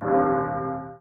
end_of_invincibility_warning.ogg